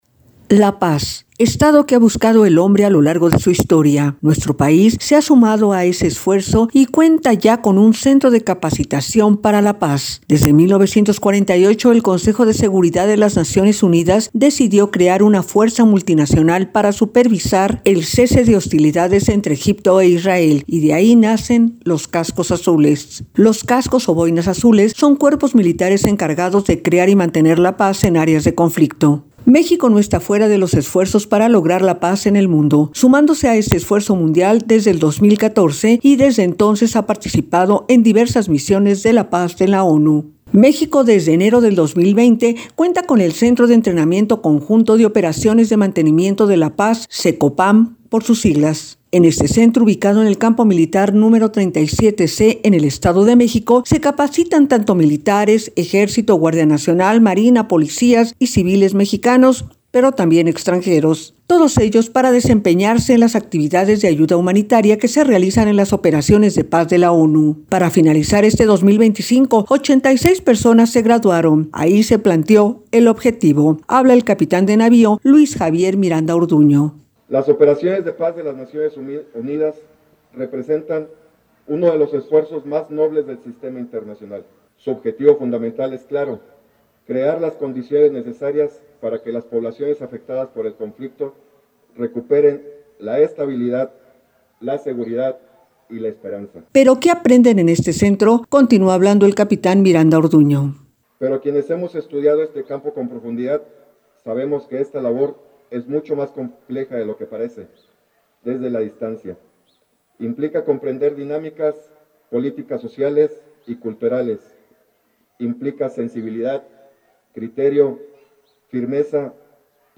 NOTA-DEFENSA-PAZ-PARA-RADIO.mp3